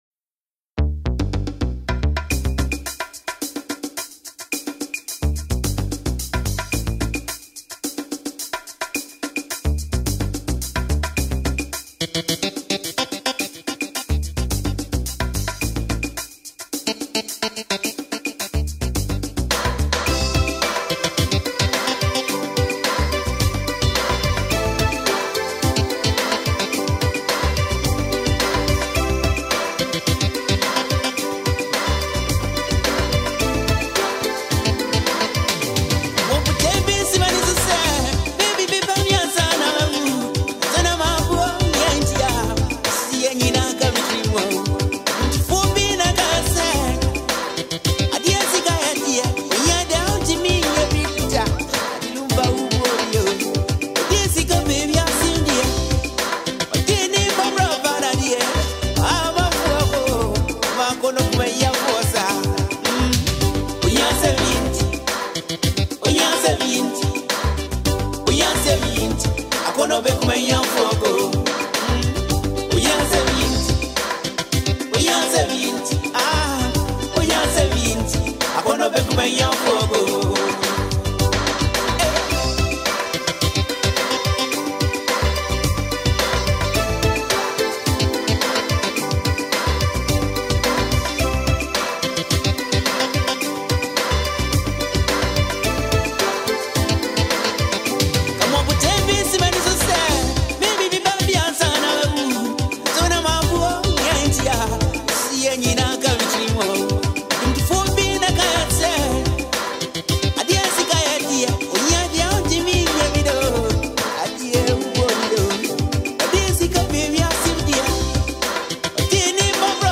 Ghanaian highlife
soothing rhythm, and emotional depth